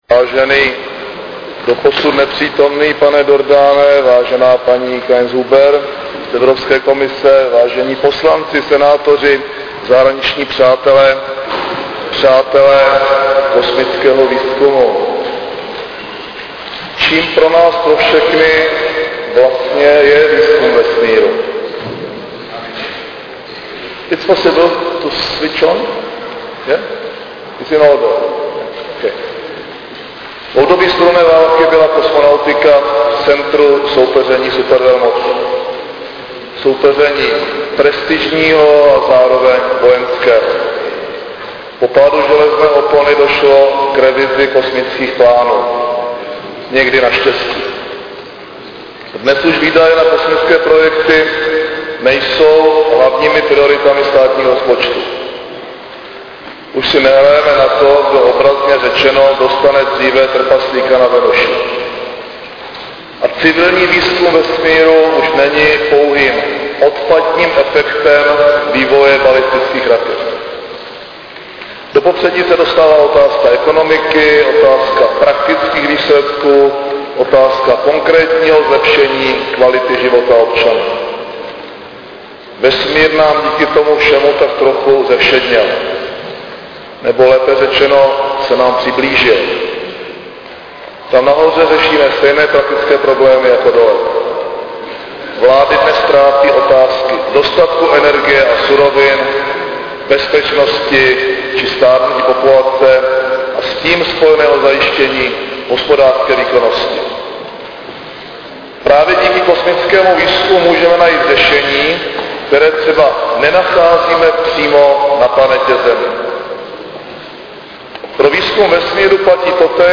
Předseda vlády Mirek Topolánek dnes (13. 10.) v prostorách Senátu zahájil 10. ročník konference, která se bude zabývat zejména rolí Evropské unie ve vesmírném výzkumu.
Zvukový záznam projevu předsedy vlády Mirka Topolánka